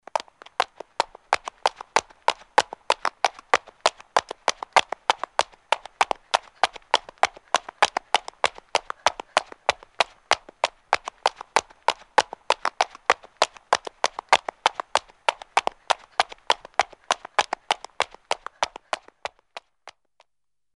小跑着的高跟鞋1.mp3
通用动作/01人物/01移动状态/高跟鞋/小跑着的高跟鞋1.mp3
• 声道 立體聲 (2ch)